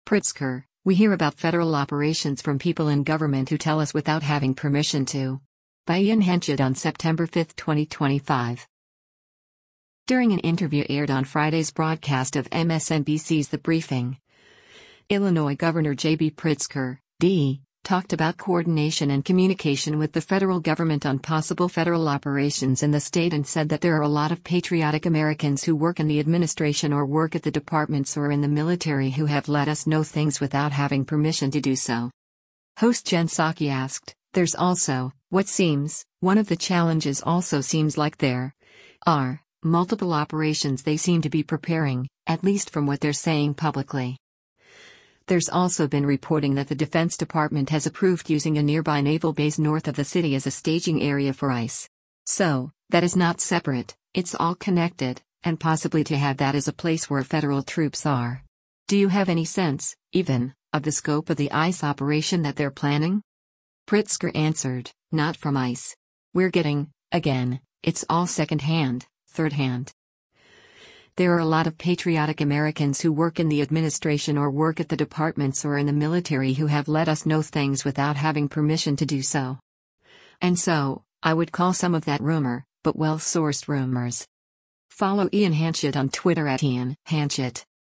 During an interview aired on Friday’s broadcast of MSNBC’s “The Briefing,” Illinois Gov. JB Pritzker (D) talked about coordination and communication with the federal government on possible federal operations in the state and said that “There are a lot of patriotic Americans who work in the administration or work at the departments or in the military who have let us know things without having permission to do so.”